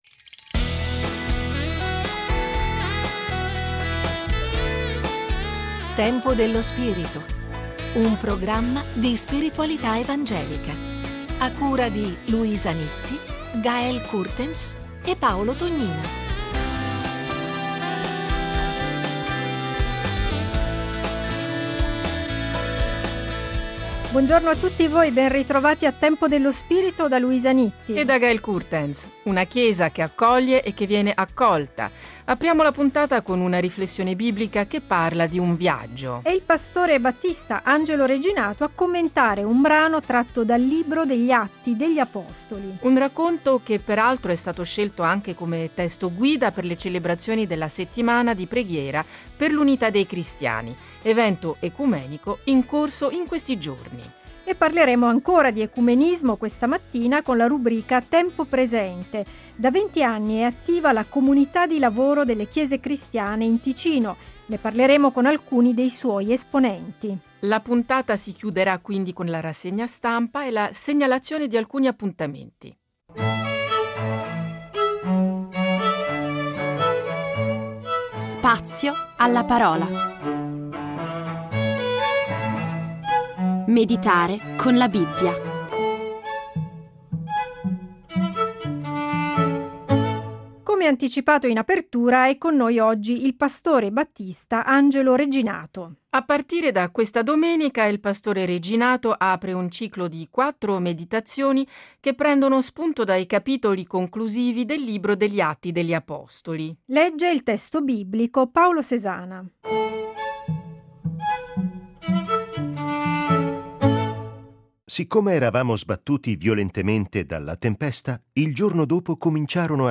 Compie 20 anni la Comunità di lavoro delle chiese cristiane del Canton Ticino. Interviste con alcuni dei suoi responsabili.